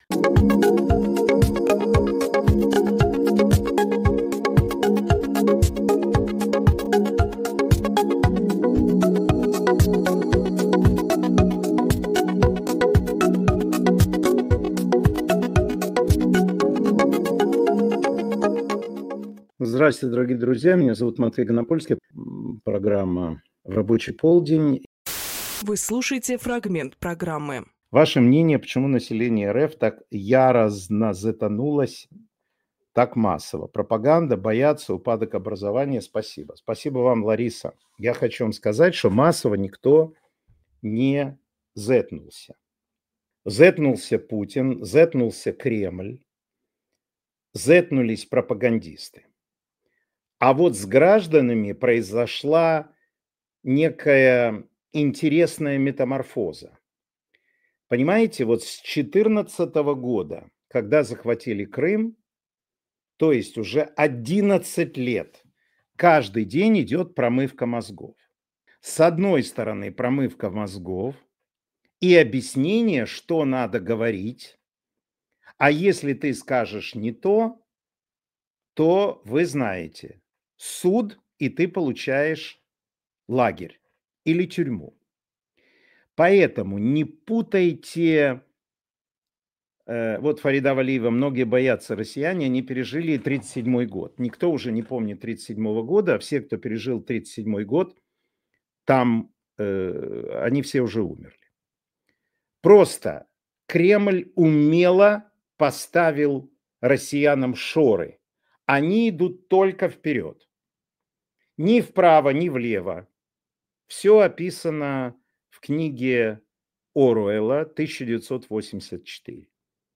Матвей Ганапольскийжурналист
Фрагмент эфира от 08.07.25